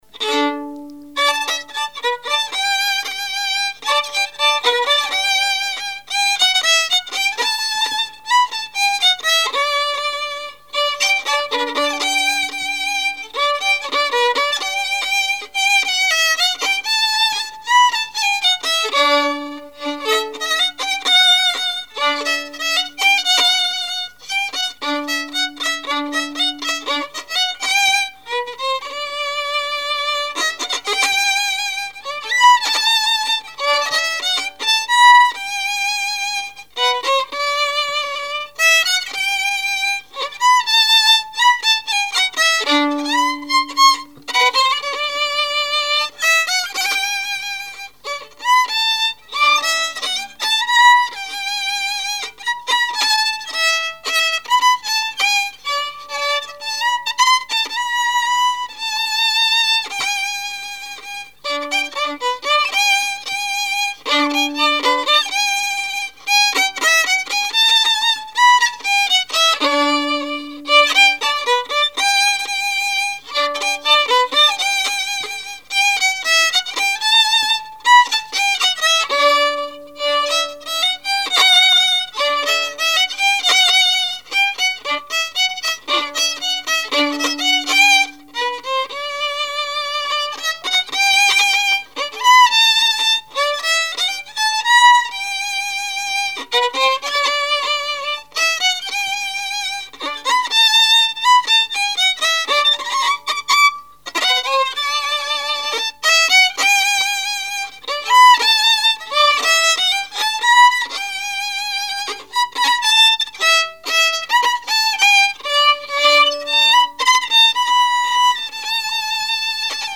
musique varieté, musichall
danse : marche
Répertoire musical au violon
Pièce musicale inédite